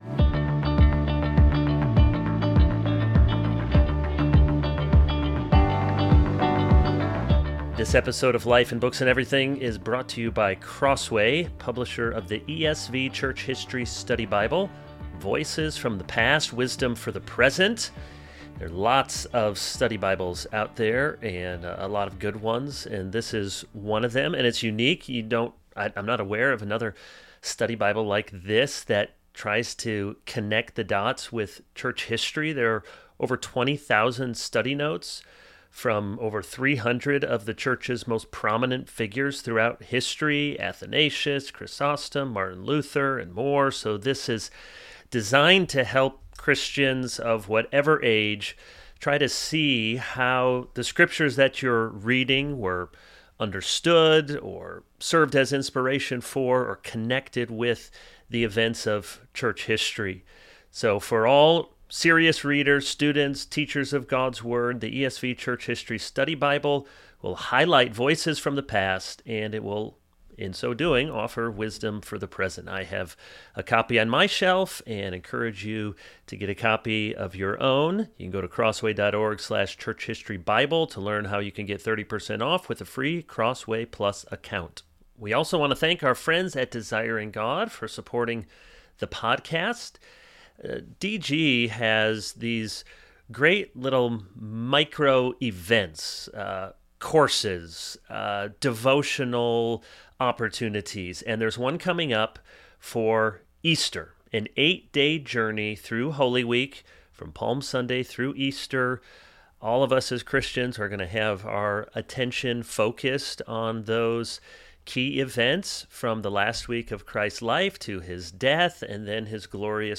If you don’t like freewheeling conversation, laughter, and banter among friends, then this may not be the episode for you.